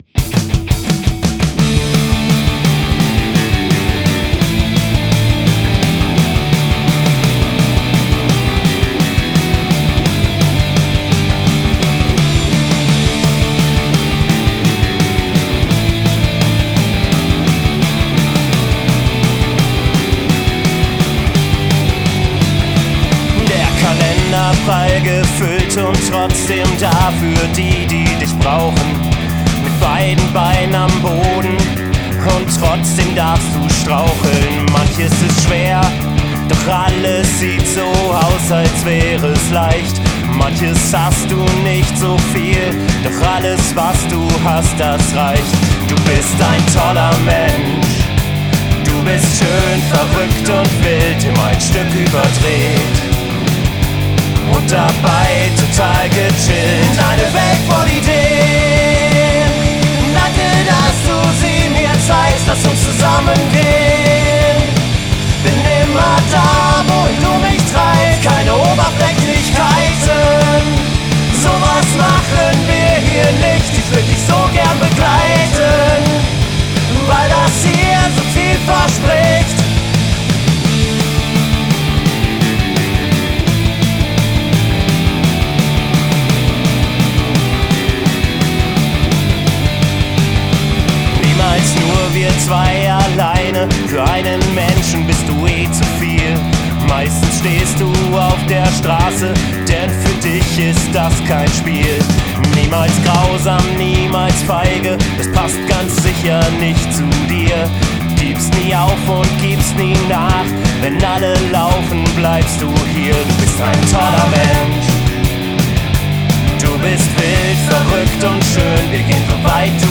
Punk-Rock Lovesong - final?